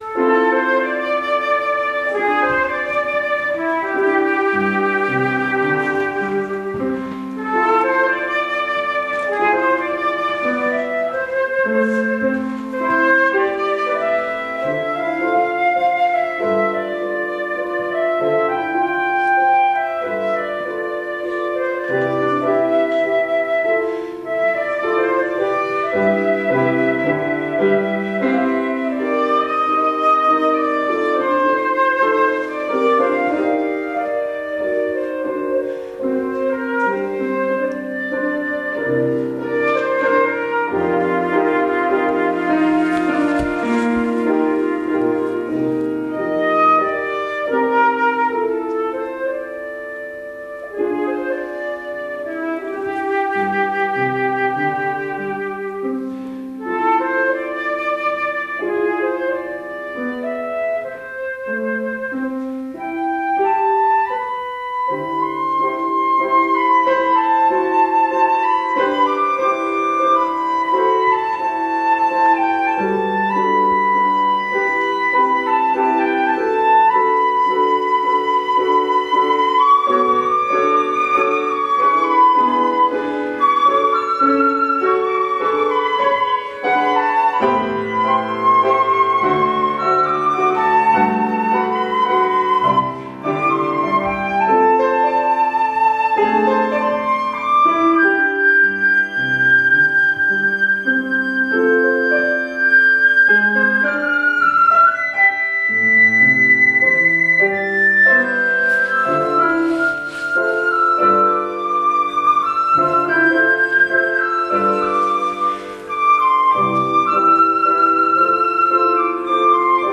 zongorista